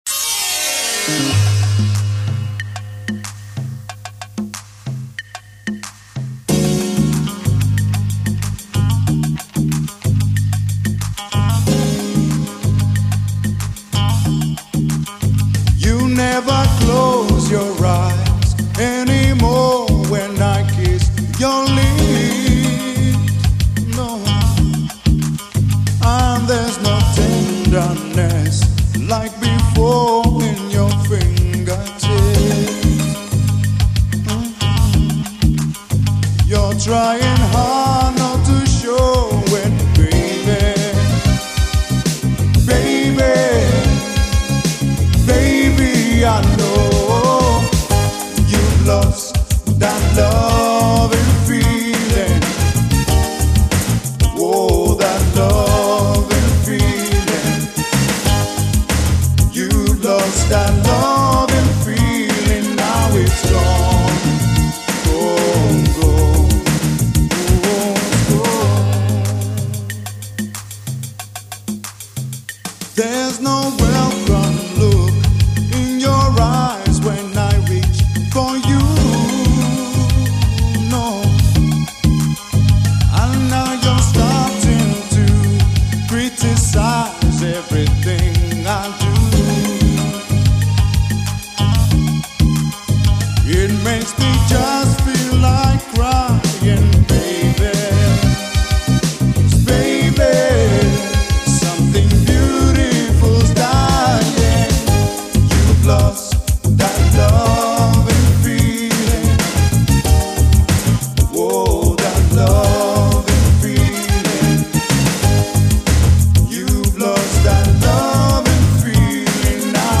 glide smoothly over the top of the latino instrumentation